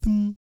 Index of /90_sSampleCDs/ILIO - Vocal Planet VOL-3 - Jazz & FX/Partition B/4 BASS THUMS